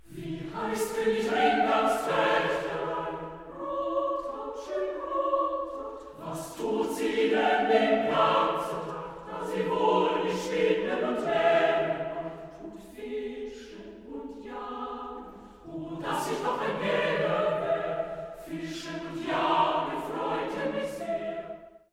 chormusikalisches A-cappella-Werk
für gemischten Chor und Frauenstimmen